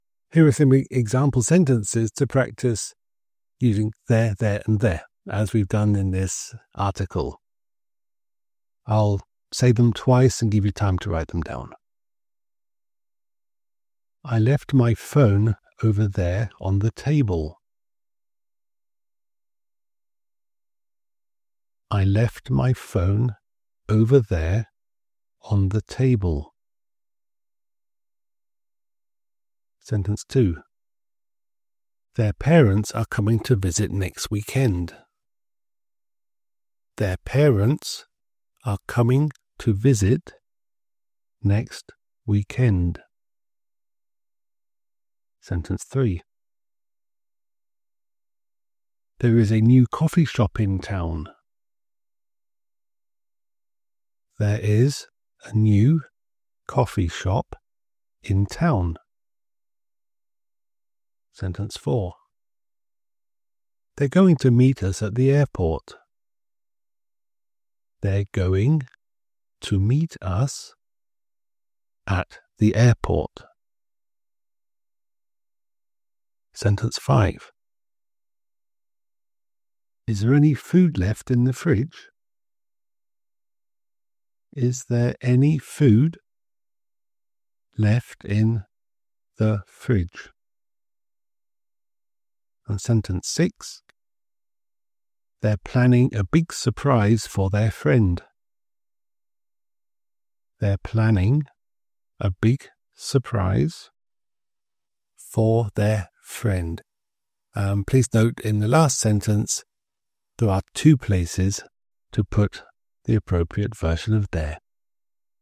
Dictation Exercises
🎤 Dictation Single Sentences: